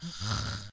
peach_snoring1.ogg